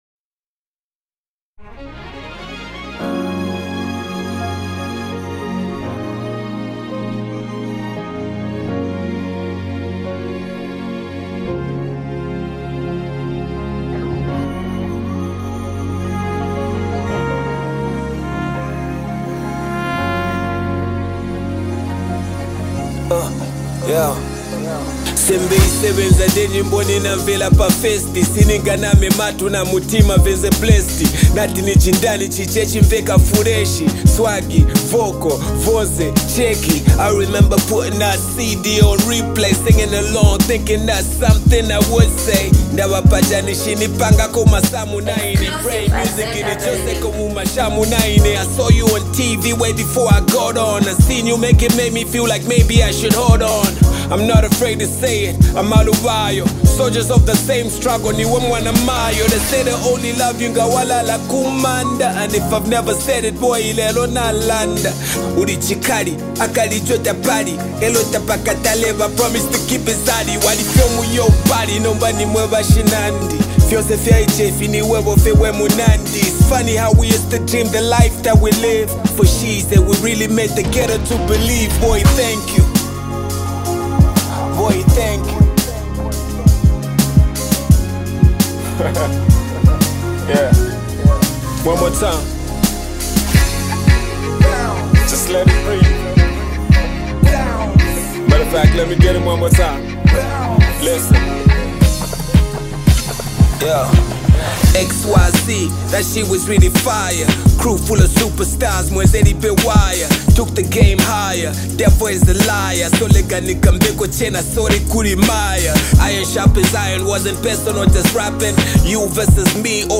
Zambian music
rapper